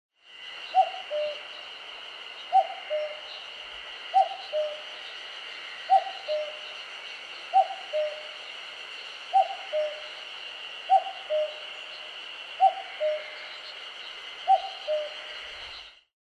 カッコウ　Cuculus canorusカッコウ科
奥日光千手ヶ浜　alt=1270m  HiFi --------------
Rec.: SONY TC-D5M
Mic.: audio-technica AT822
他の自然音：　 エゾハルゼミ